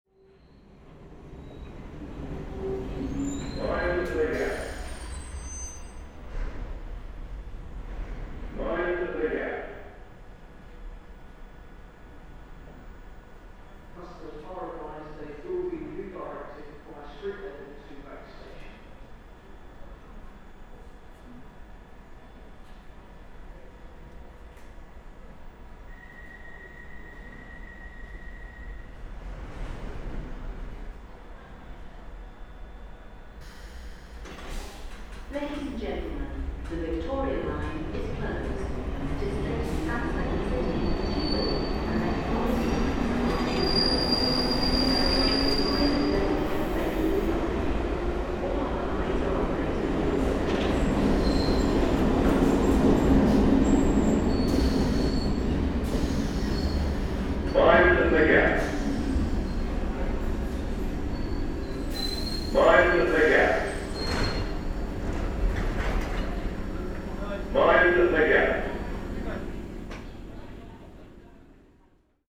"Mind the Gap" are humorous in a slightly surreal way, coming to you like the voice of Big Brother in level tones over ancient speakers.